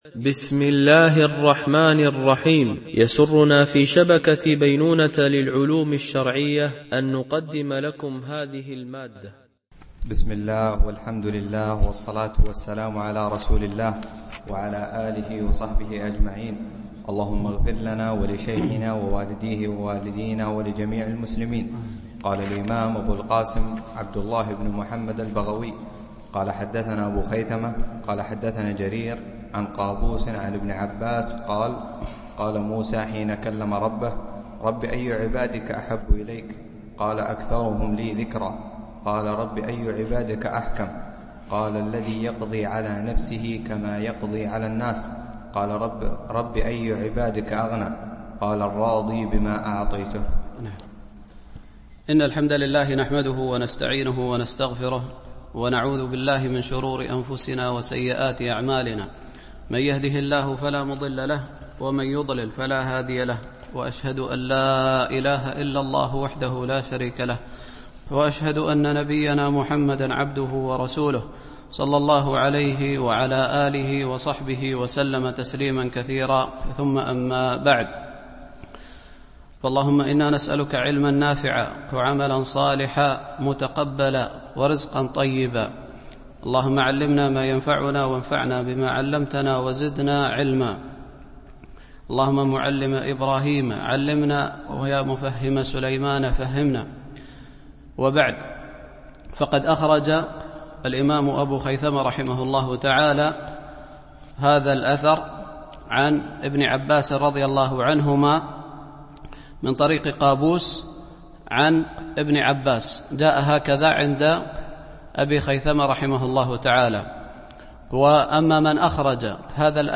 شرح كتاب العلم لأبي خيثمة ـ الدرس 29 (الأثر 86-87)